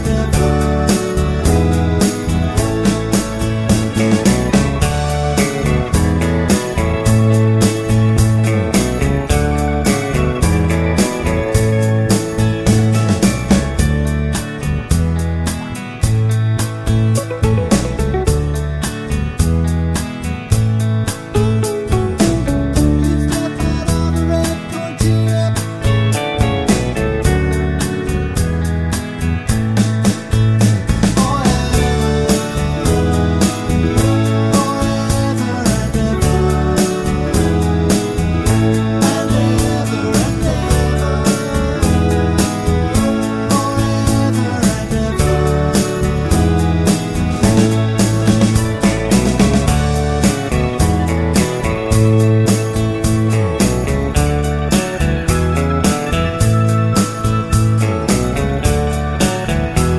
no Backing Vocals Irish 3:12 Buy £1.50